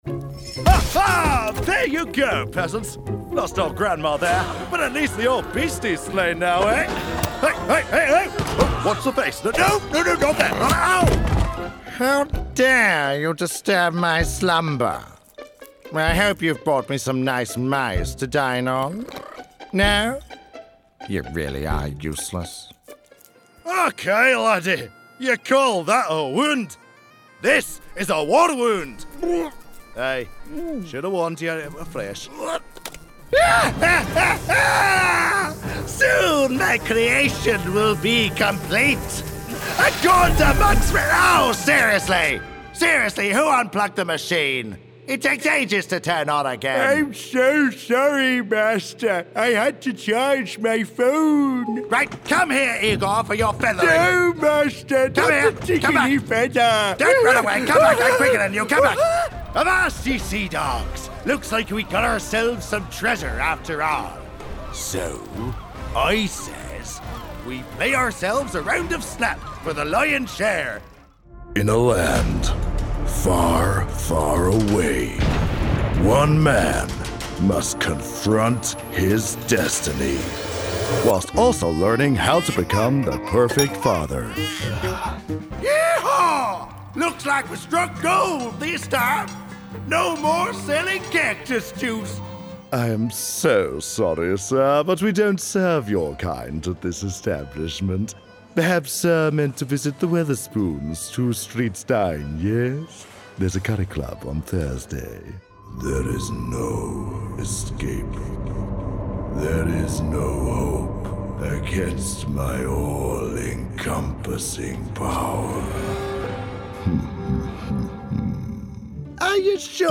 Deep, Classic, Genuine